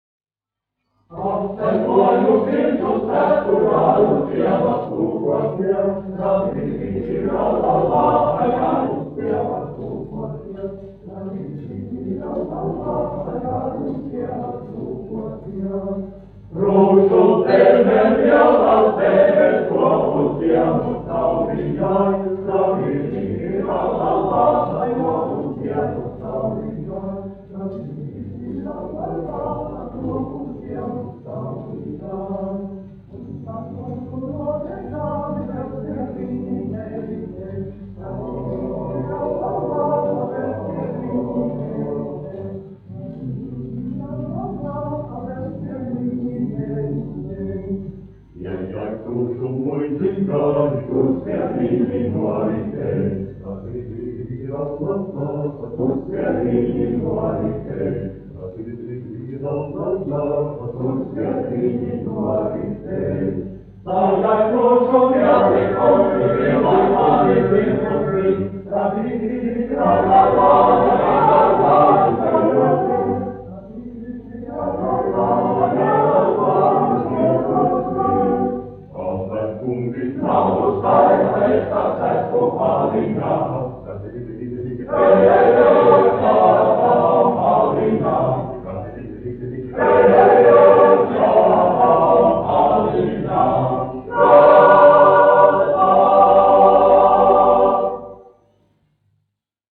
Zuikas vīru koris, izpildītājs
1 skpl. : analogs, 78 apgr/min, mono ; 25 cm
Latviešu tautasdziesmas
Kori (vīru)
Skaņuplate